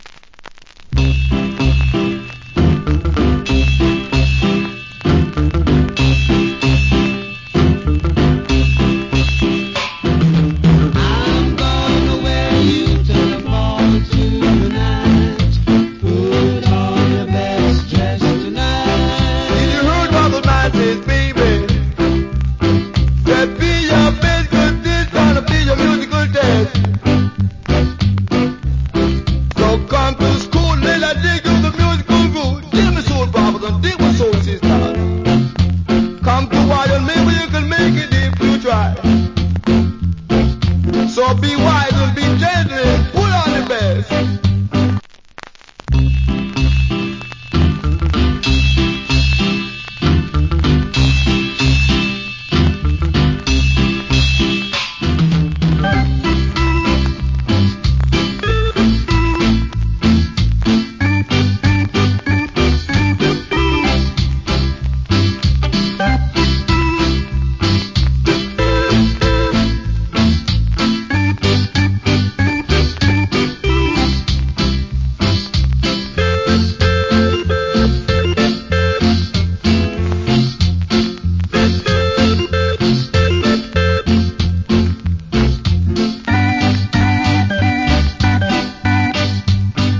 Great DJ.